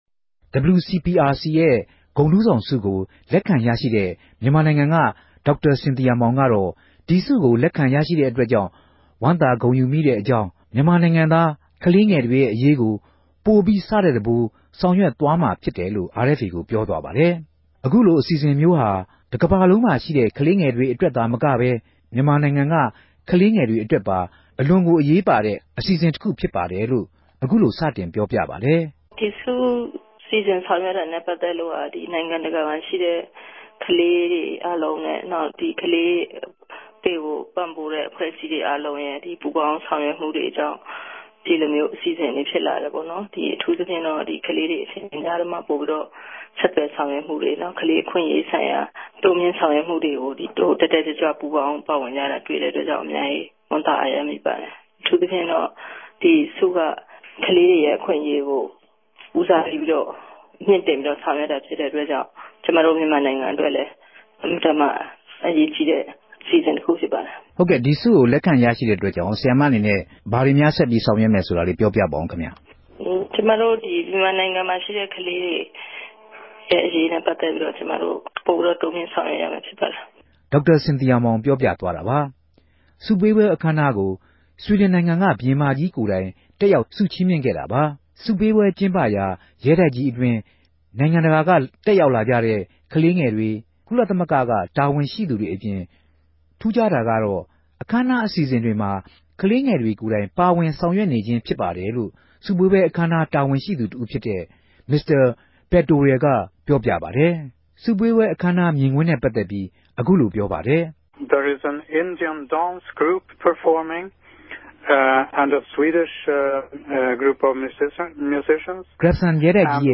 ဒေၝက်တာစင်သီယာမောင် ဆုရရြိခဲ့တာနဲႛပတ်သက်္ဘပီး ဆြီဒငိံိုင်ငံမြာနေထိုင်တဲ့ ခဵင်းအမဵိြးသမီးတဦးက ခုလိုေူပာူပပၝတယ်။